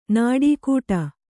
♪ nāḍī kūṭa